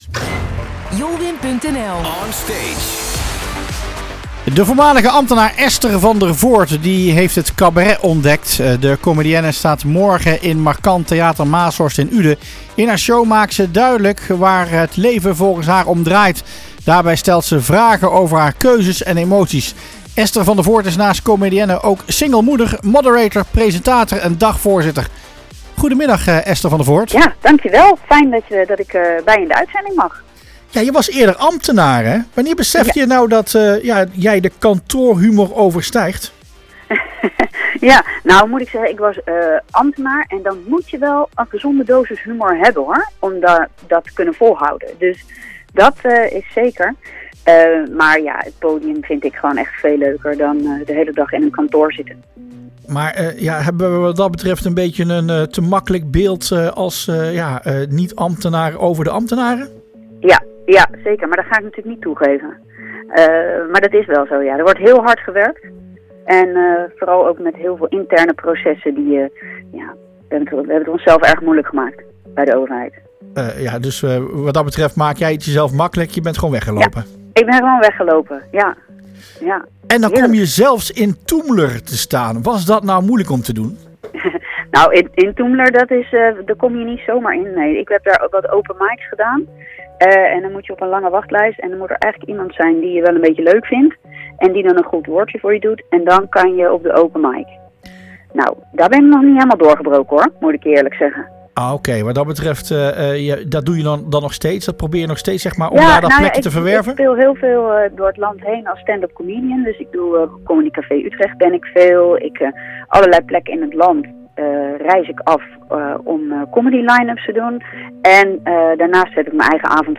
Interview – Pagina 3